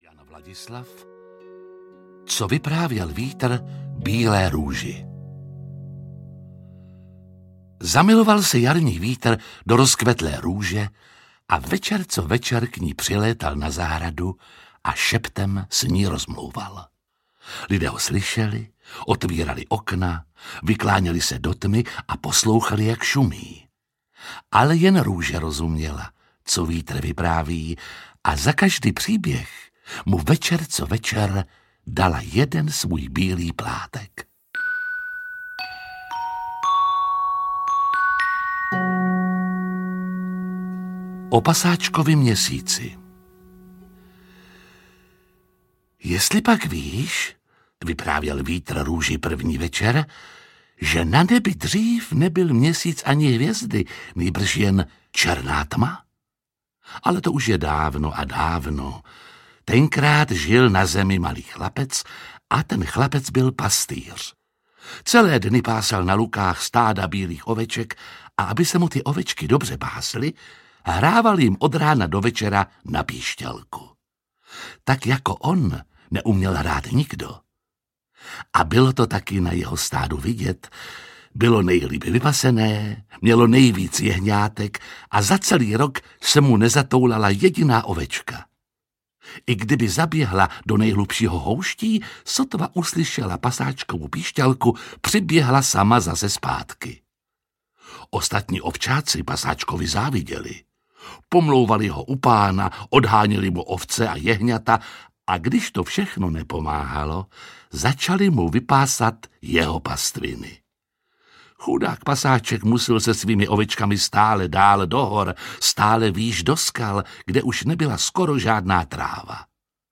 Co vyprávěl vítr bílé růži audiokniha
Ukázka z knihy
• InterpretJiří Lábus